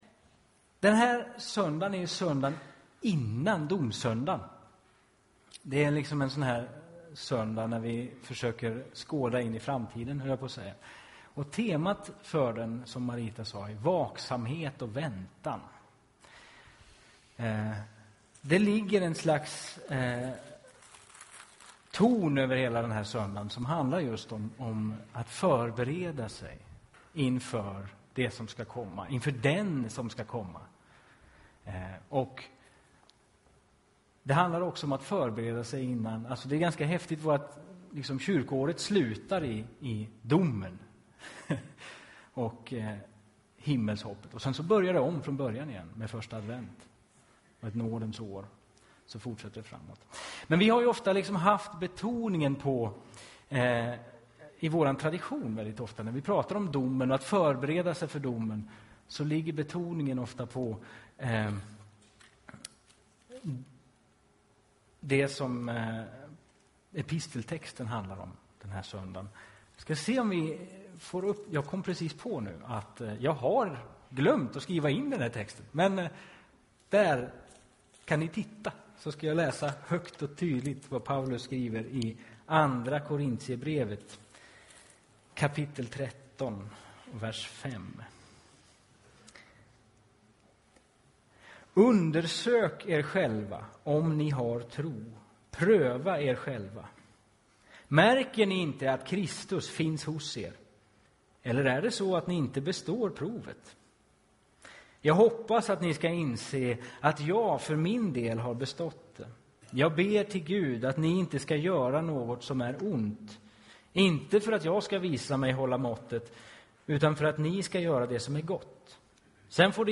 predikar.